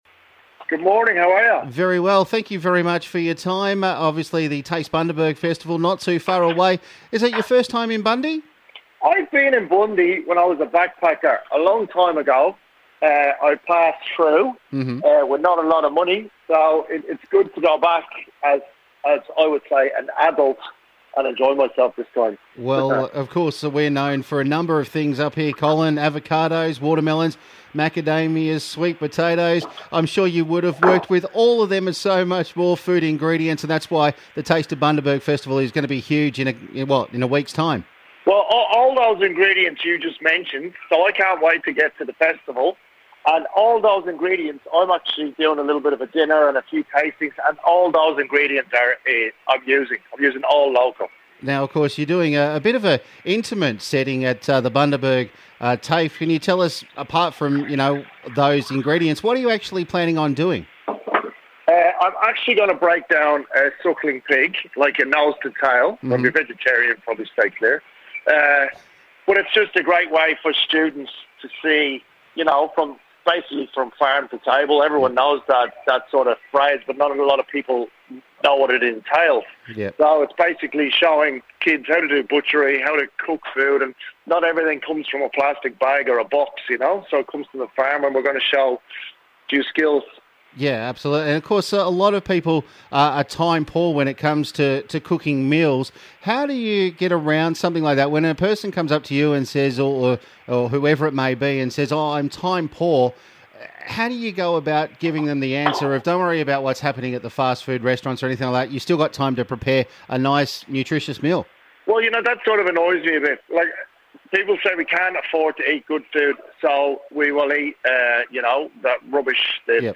a chat on The Big Brekky to talk about what he is doing while in the region.